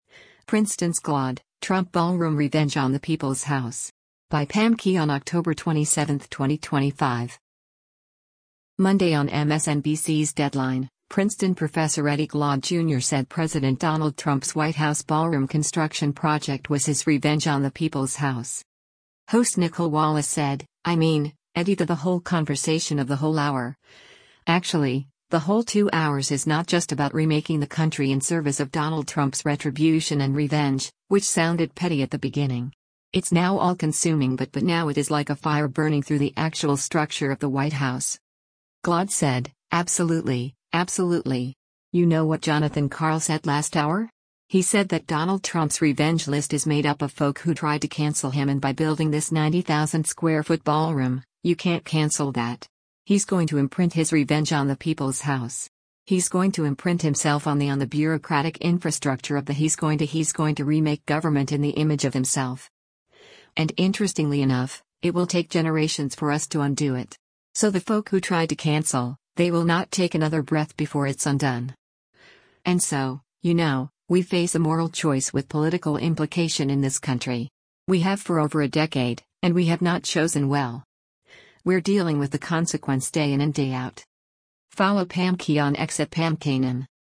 Monday on MSNBC’s “Deadline,” Princeton professor Eddie Glaude, Jr. said President Donald Trump’s White House ballroom construction project was “his revenge on the people’s house.”